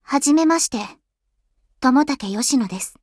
実際にVALL-E-Xを用いて、hugging-faceのサンプル音声から、音声を生成してみます。